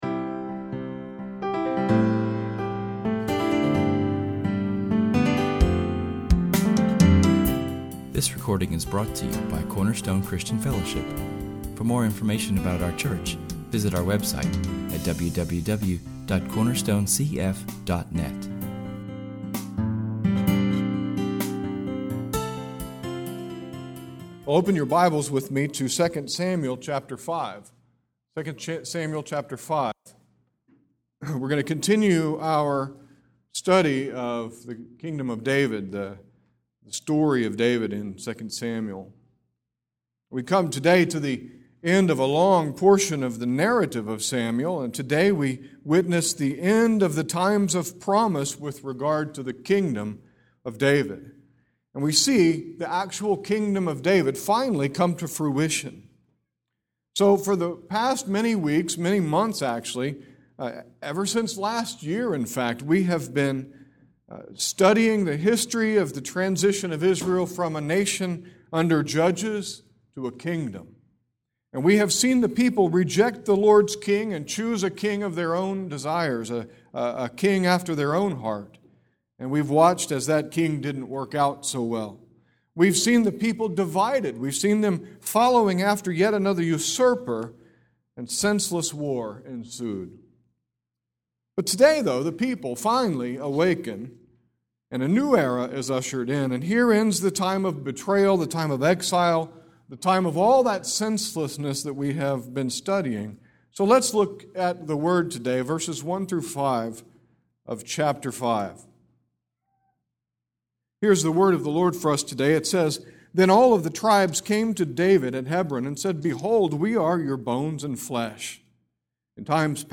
Our sermon for today is taken from [esvignore]2 Samuel 5:1-5[/esvignore]. Finally, after all of this time, David is crowned King of all Israel. We will examine four characteristics of David’s Kingdom as revealed in our text, and will then learn what sort of response is appropriate for such a king as David.